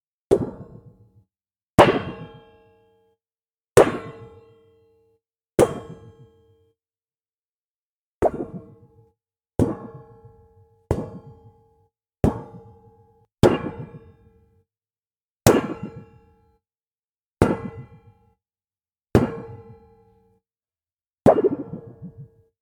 sheet metal sound four: Bang
bam boing boom cartoon comedy frying-pan fun funky sound effect free sound royalty free Funny